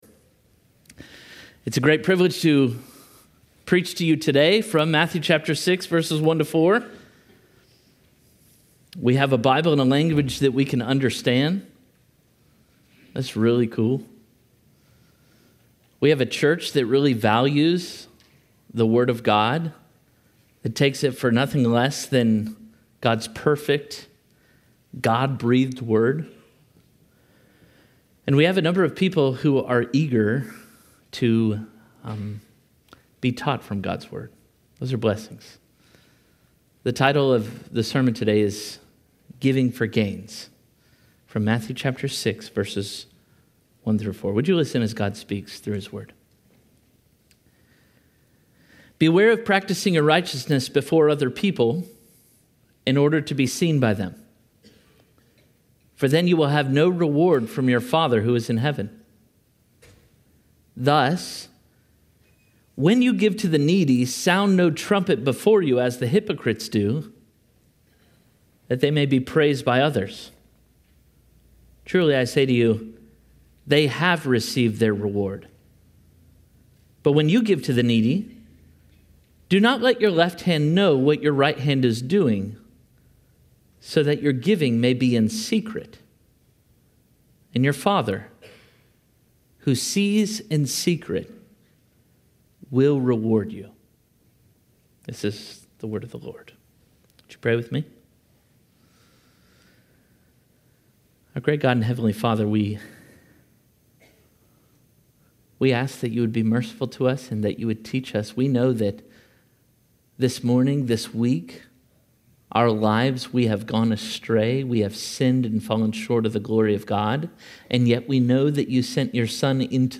Parkway Sermons